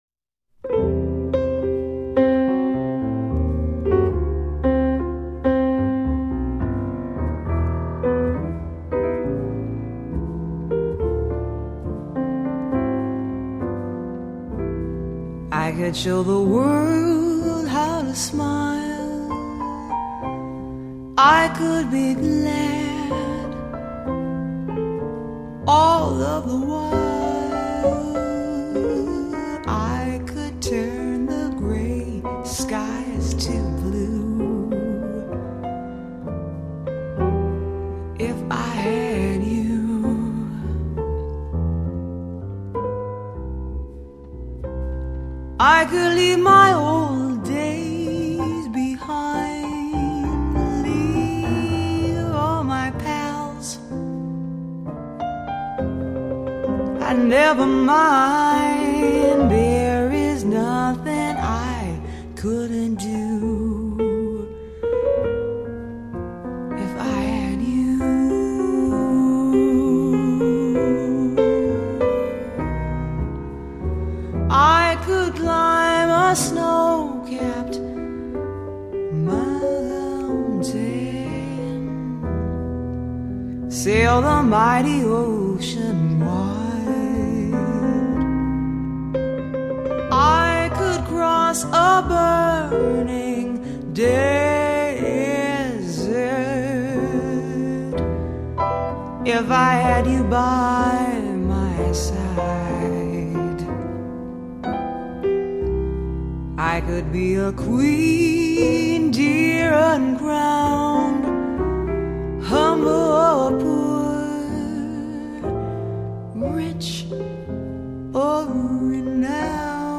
音乐类型：爵士乐
而她的嗓音就像是加了蜂密的威士忌，一醉却令人无法抗拒。
她的嗓音就像是加了蜂密的威士忌，易醉卻令人無法抗拒